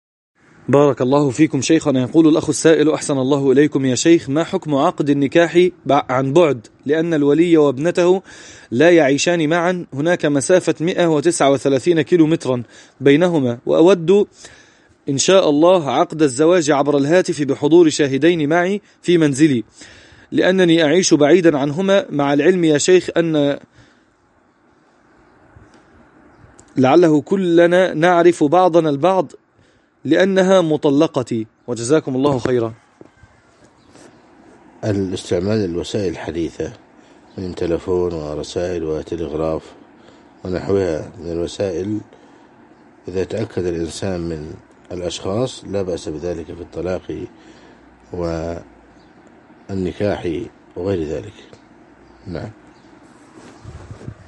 الفتوى
جواب السؤال صوتي